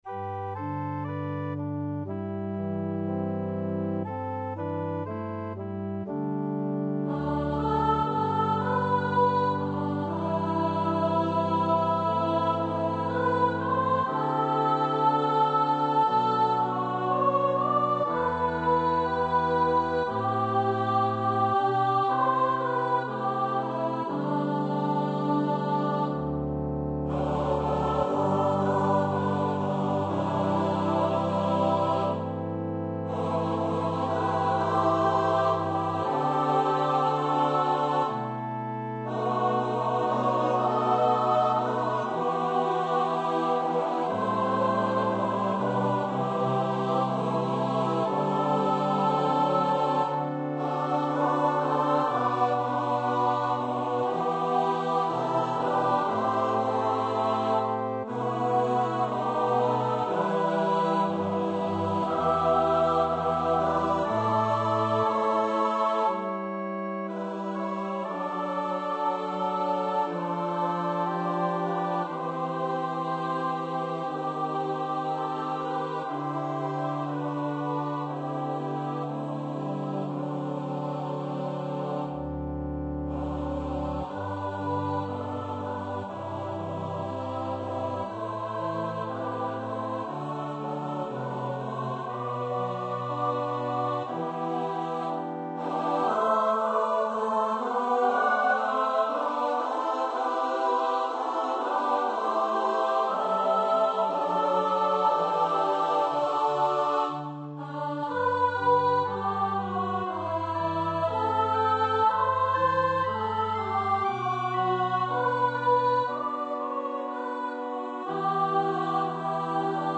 for mixed voice choir and organ
Choir - Mixed voices (SATB)